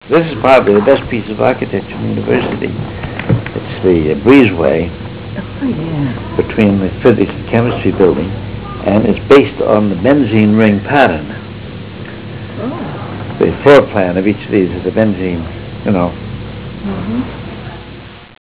148Kb Ulaw Soundfile Hear Ansel Adams discuss this photo: [148Kb Ulaw Soundfile]